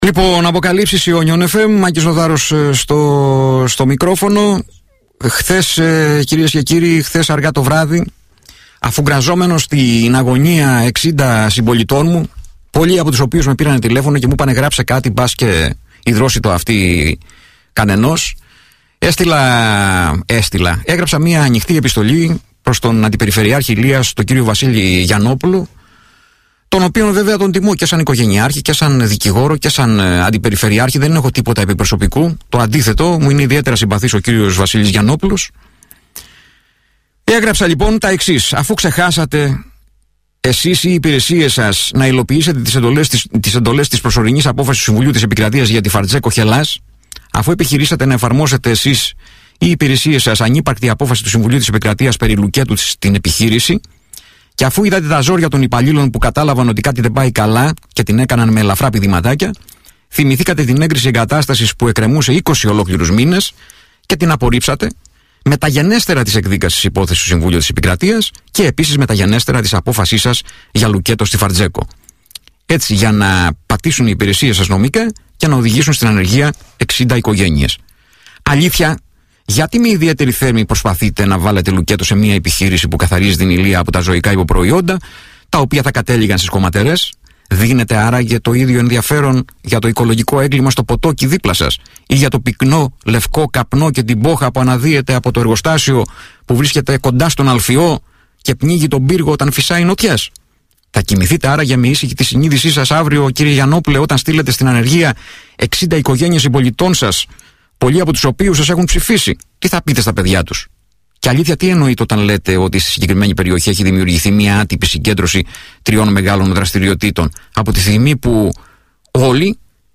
Η συνομιλία του αντιπεριφερειάρχη Ηλείας Βασίλη Γιαννόπουλου